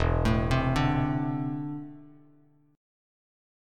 E7sus4#5 Chord